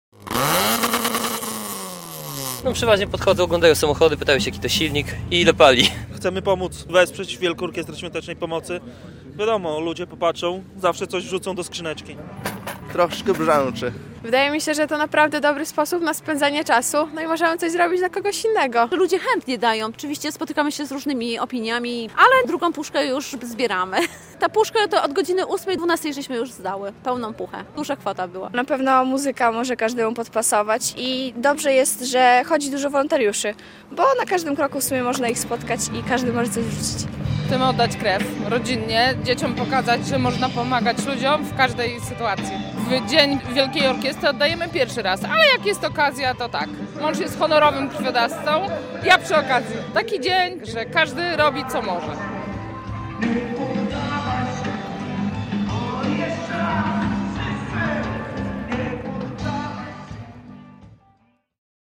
Samochody służb mundurowych i rajdowe atrakcją białostockiego finału WOŚP - relacja